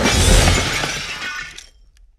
CosmicRageSounds / ogg / general / combat / megasuit / crash1.ogg
crash1.ogg